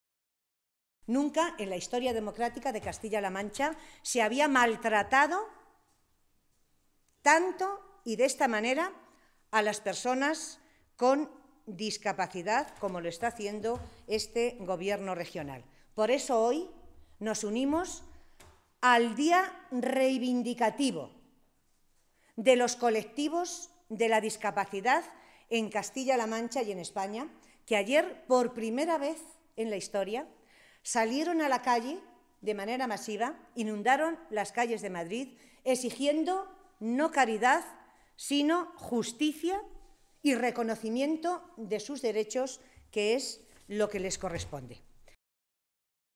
Matilde Valentín, portavoz de Asuntos Sociales del Grupo Socialista
Cortes de audio de la rueda de prensa